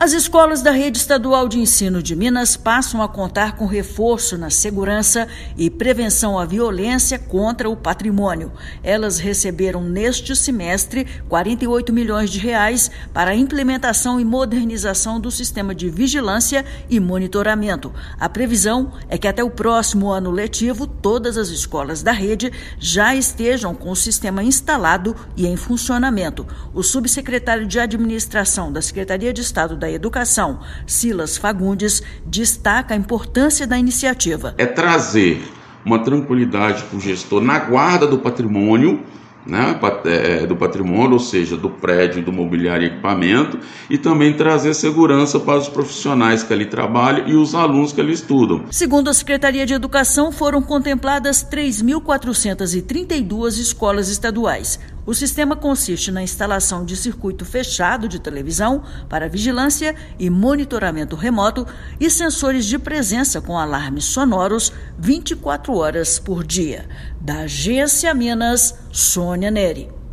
As escolas da rede estadual de ensino de Minas Gerais passam a contar com um importante reforço na segurança das unidades de ensino e prevenção à violência contra o patrimônio escolar. Ouça matéria de rádio.